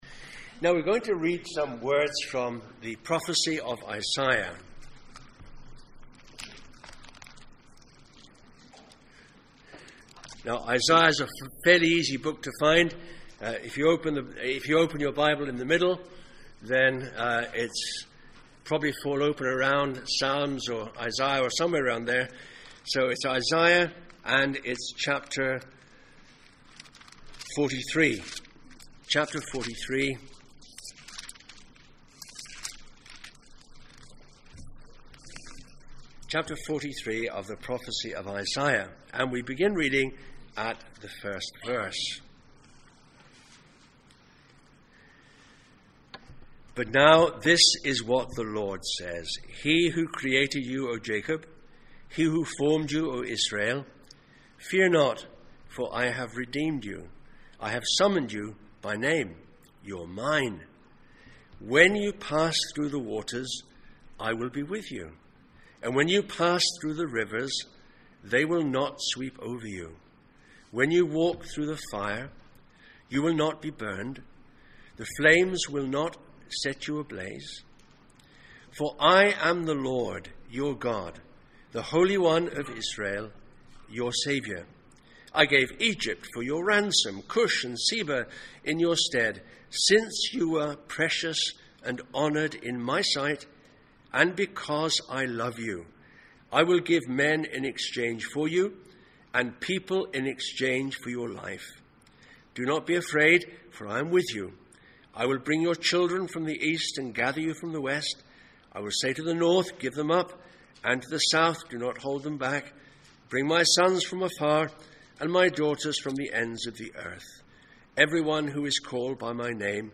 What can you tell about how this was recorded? Mark Passage: Isaiah 43:1-13, Mark 5:1-20 Service Type: Sunday Morning